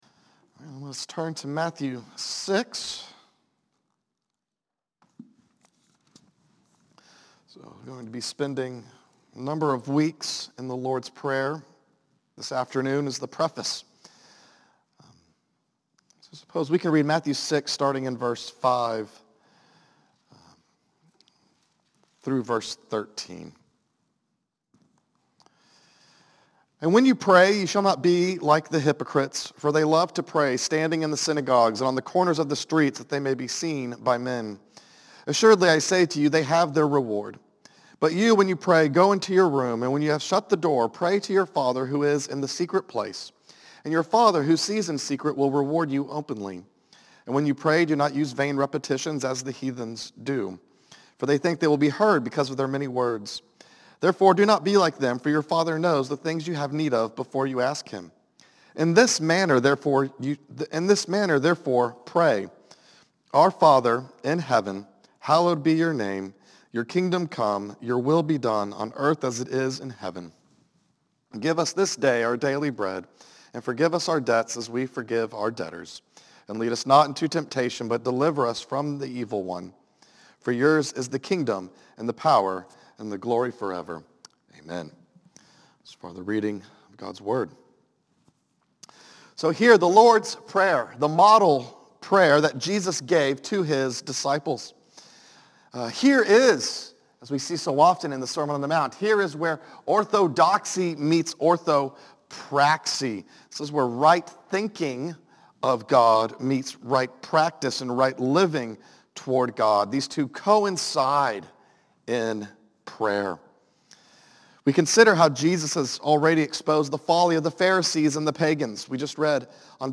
Afternoon Service